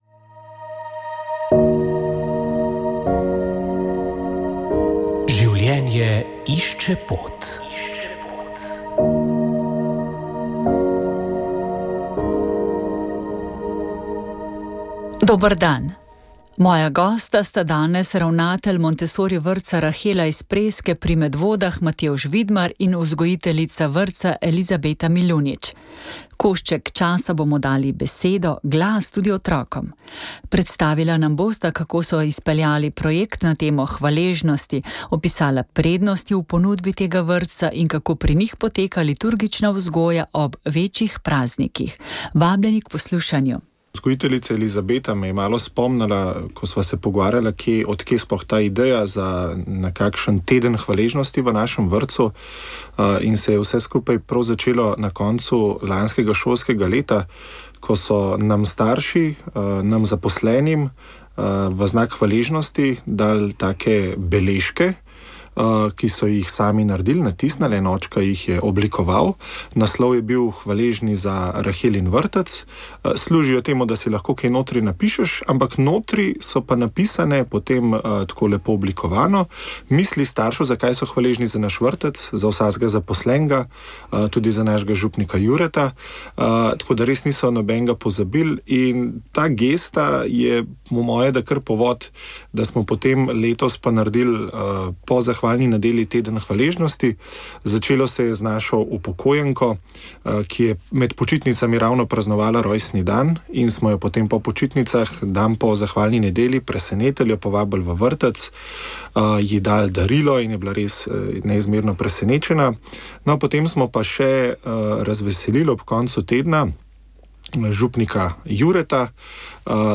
Molili so Molilci pobude Molitev in post za domovino